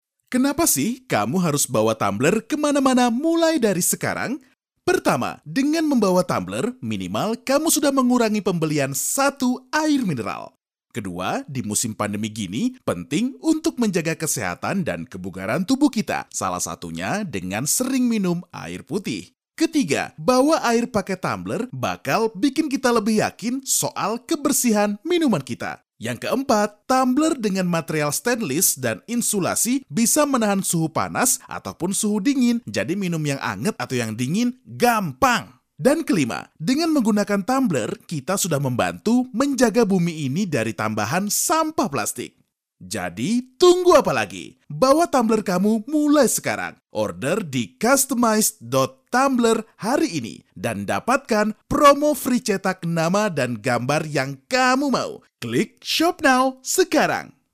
• 1Indonesian Male No.1
Humorous and relaxed